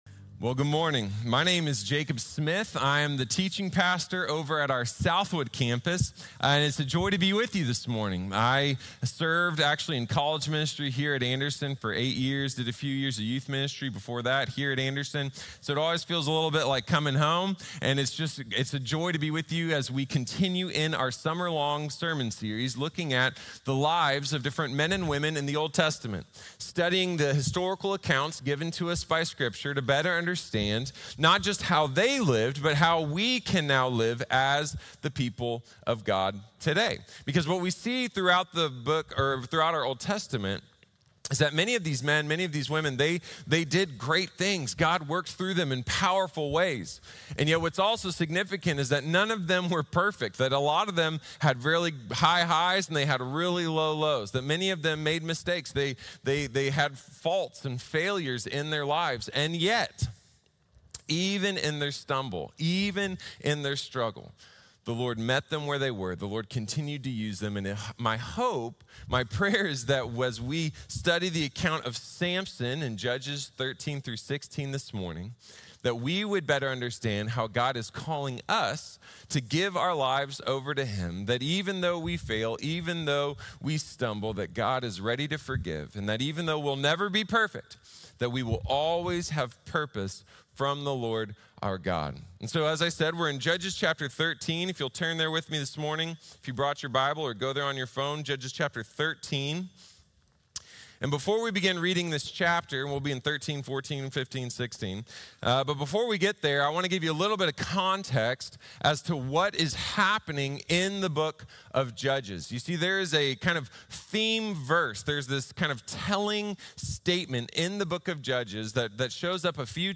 Samson | Sermon | Grace Bible Church